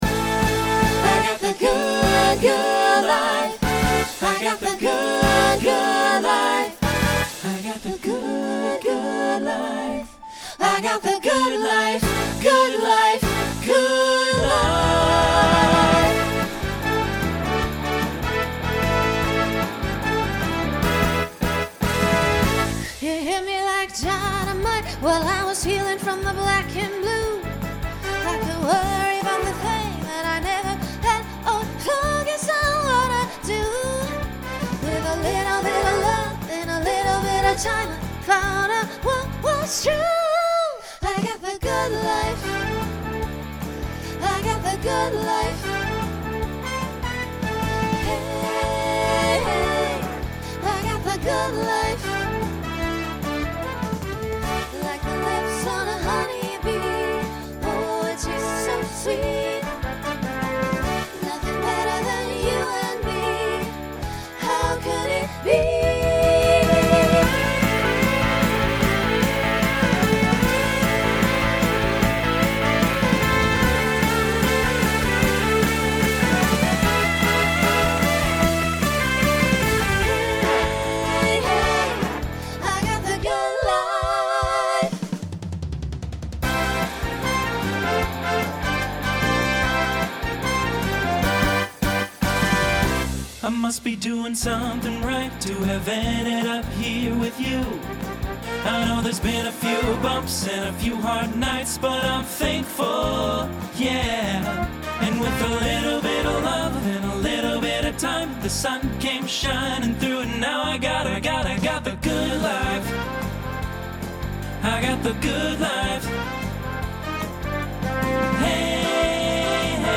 Voicing Mixed